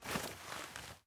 svd_draw.ogg